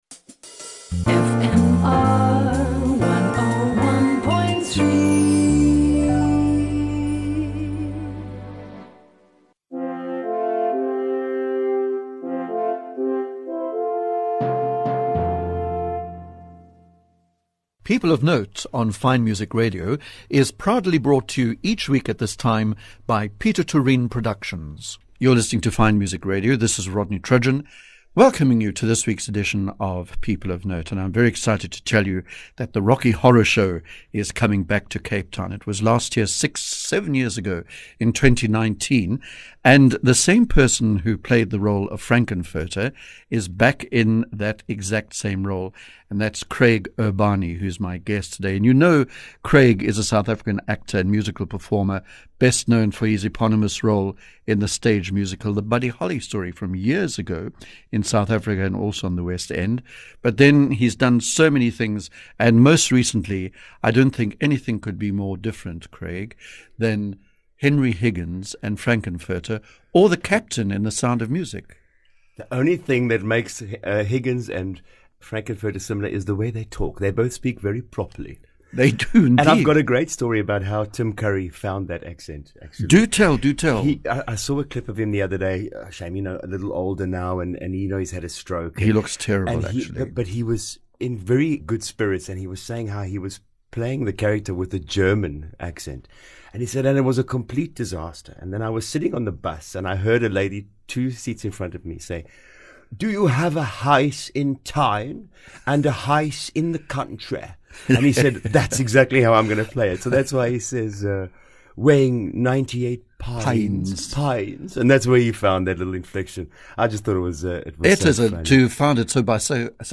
Whether their fields are music, literature, theatre, dance, opera or even politics and economics, well invite them into the studio to talk about their lives, inspirations and role models, among other things. Rather like the famous BBC programme, Desert Island Discs, Ill ask my guests to bring with them their six or seven favourite pieces of music to share with us.